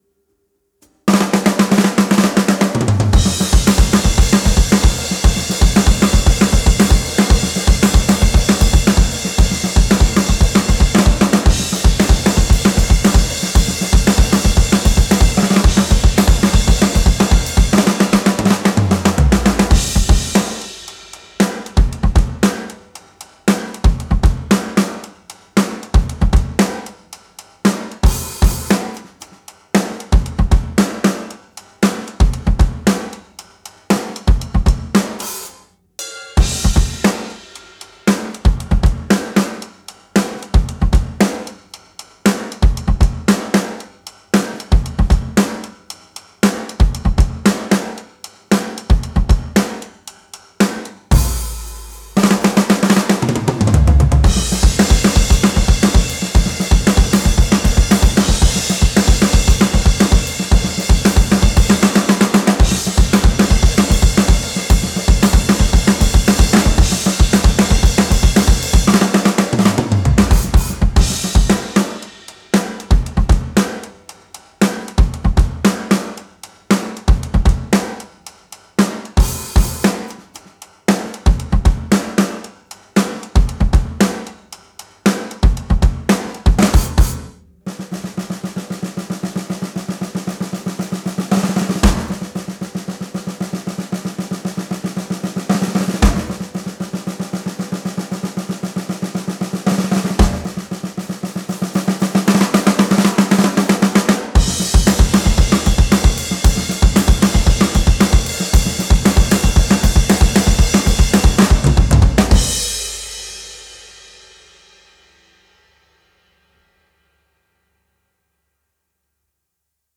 Rock
Genre:Rock, Metal
Tempo:230 BPM (4/4)
Kit:Rogers 1977 Big R 22"
Mics:14 channels